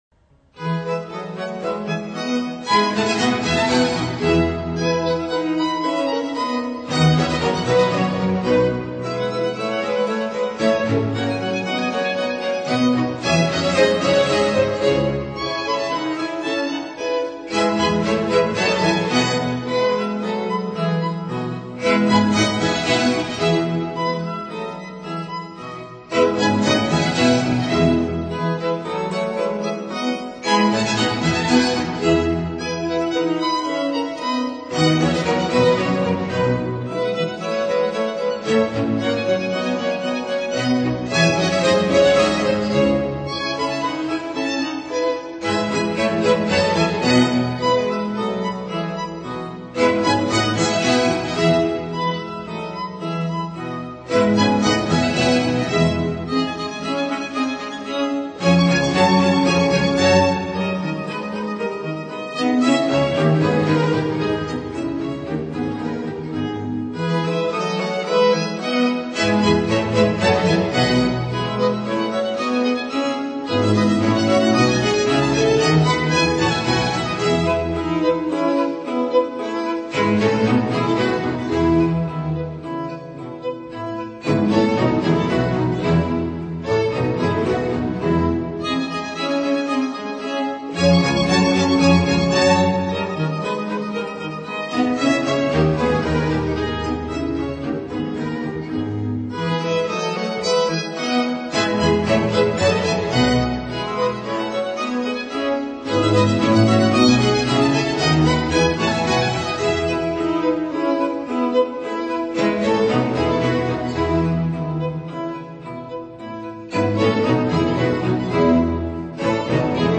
La Petite Bande
Sigiswald Kuijken, Cond
1악장 (Vivace - Allegro - Adagio - Vivace - Allegro - Largo andante)
2악장 (Allegro - Adagio)
3악장 (Grave - Andante largo)
4악장 (Allegro)
합주협주곡(Concerto Grosso)